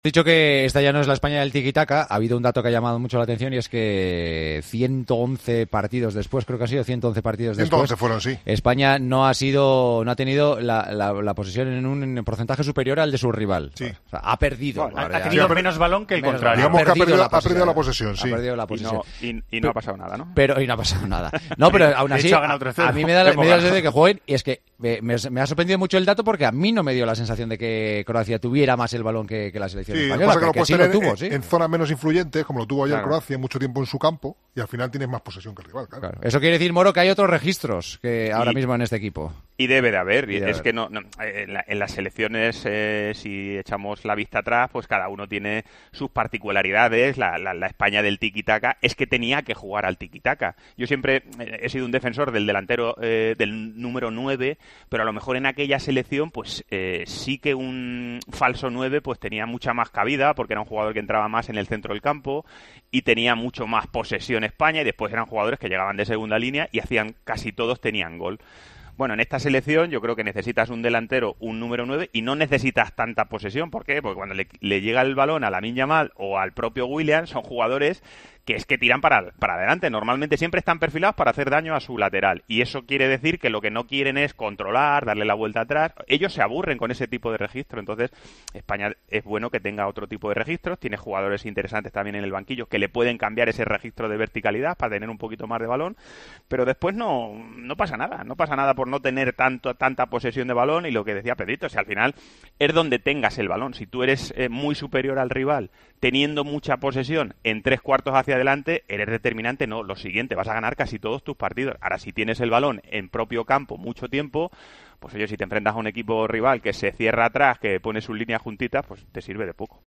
Anoche se pasó por los micrófonos de Tiempo de Juego, el exdelantero internacional Fernando Morientes.